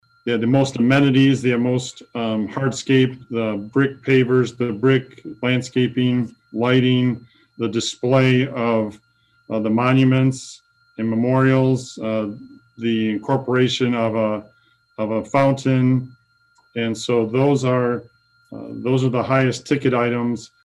City Manager Keith Baker said the half million for the Four Corners Park would be for the northeast and southeast parts of the park.